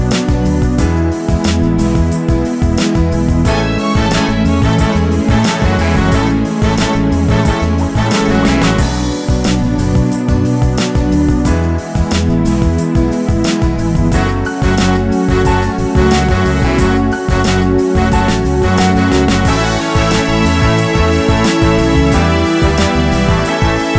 Pop (1990s)